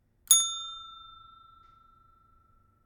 Ringing bell - happy
bell bright counter-bell desk desk-bell ding happy hotel sound effect free sound royalty free Sound Effects